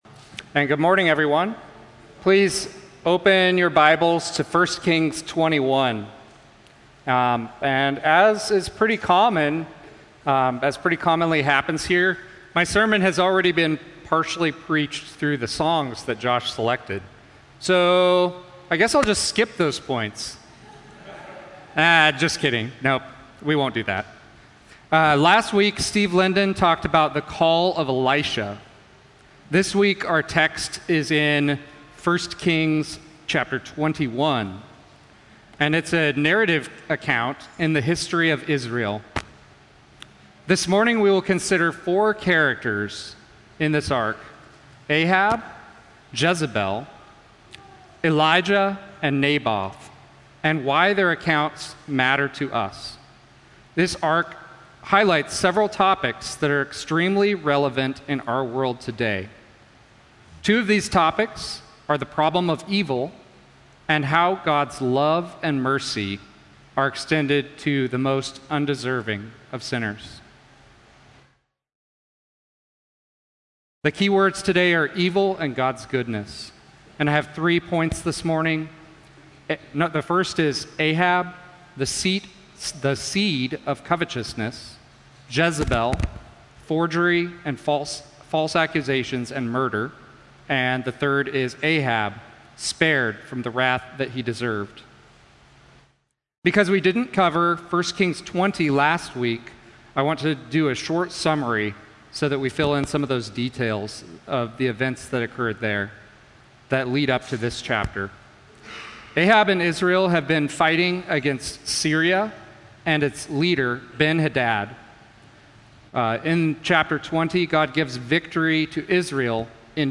A message from the series "Elijah."